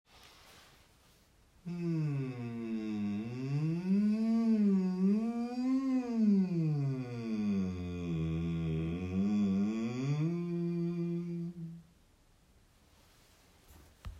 MJ is een essentiële nuance van M door de combinatie van de tong tegen het verhemelte en de M-klank die alle cellen van het lichaam in trilling brengt en de bloedsomloop stimuleert.
Oefening 1 Vrije beweging
Deze eerste oefening bestaat uit het vrij bewegen in toonhoogte en volume op de MJ klank.